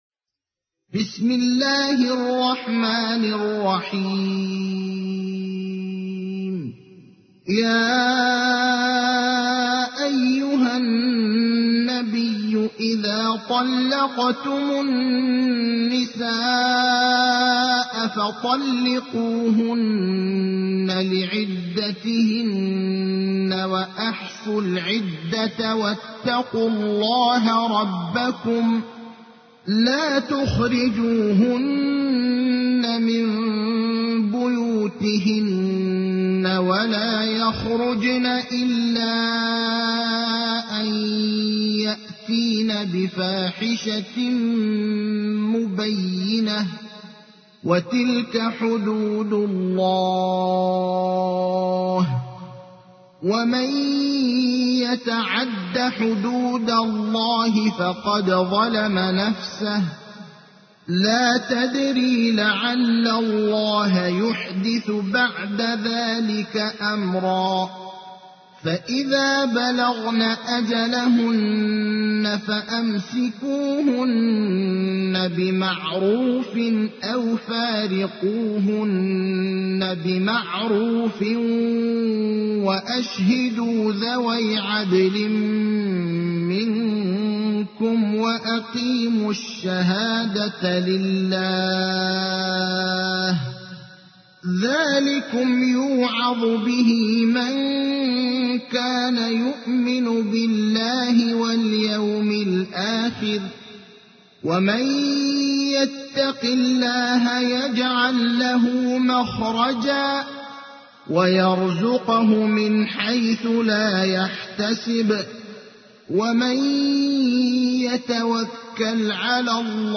تحميل : 65. سورة الطلاق / القارئ ابراهيم الأخضر / القرآن الكريم / موقع يا حسين